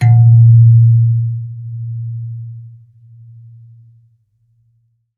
kalimba_bass-A#1-ff.wav